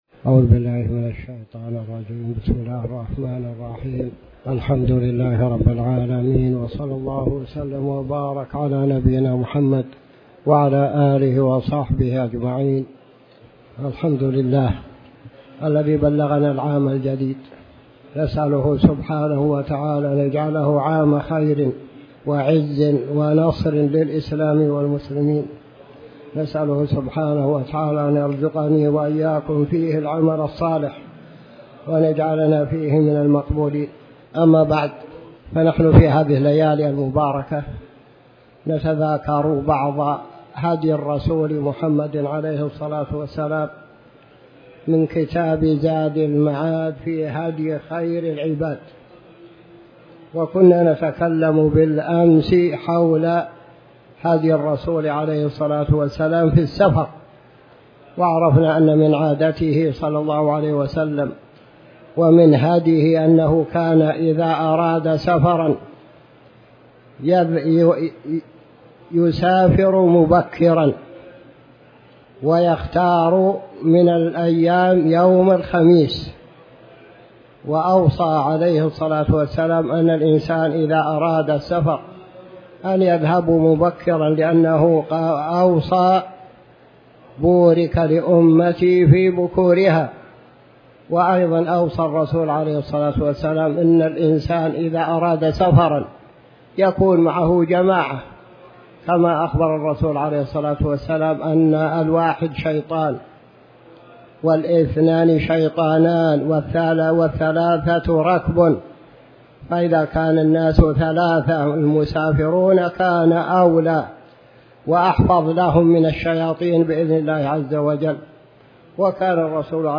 تاريخ النشر ١ محرم ١٤٤٠ هـ المكان: المسجد الحرام الشيخ